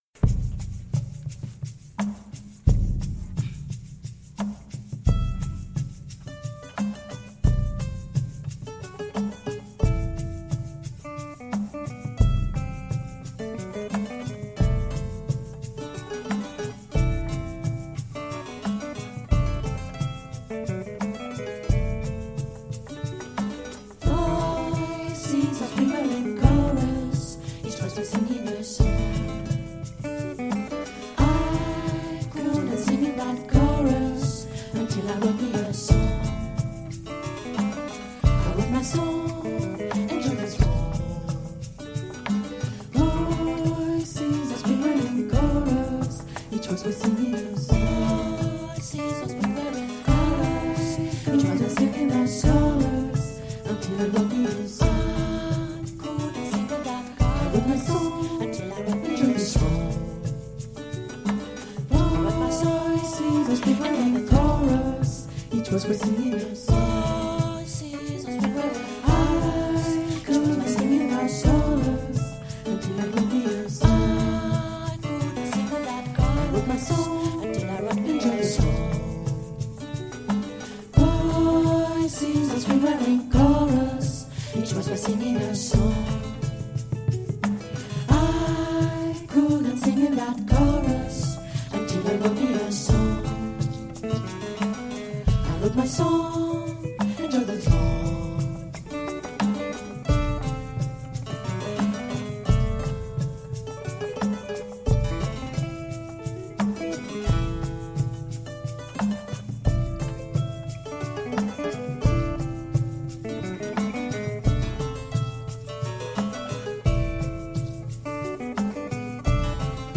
Si vous n’en avez pas, ou/et si vous ne désirez pas écouter l’acoustique de votre pièce, alors aller au numéro 1500 de la piste.
- Moondog, down is up, extraits du concert de Moondog, le 6 mai à la Rose des Vents, - Sous l’entretien, en transparence, une prise de son du train à l’arrêt en Vicchio et Florence en Italie, et une prise de son dans la forêt de Bechaoui, en Algérie.